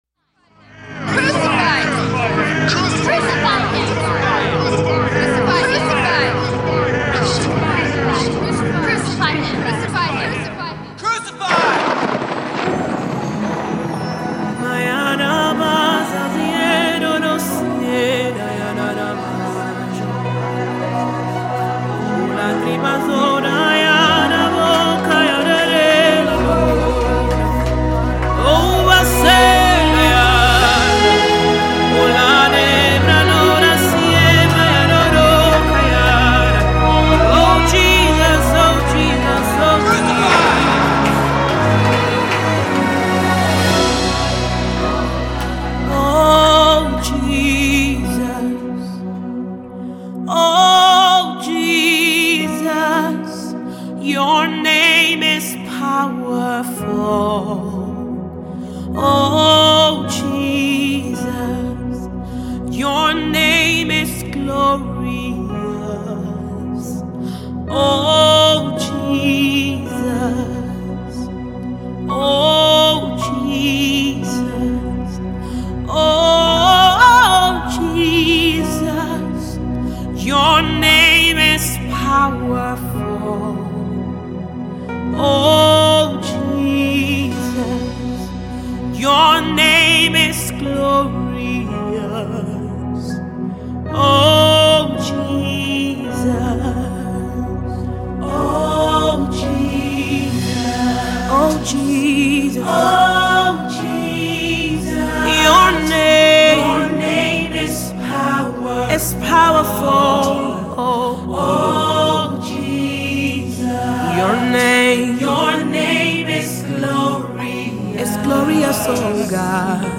gospel
worship song